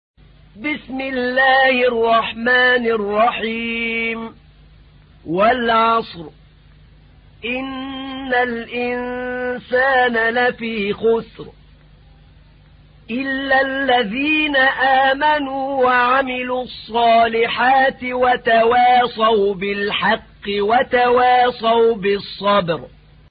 تحميل : 103. سورة العصر / القارئ أحمد نعينع / القرآن الكريم / موقع يا حسين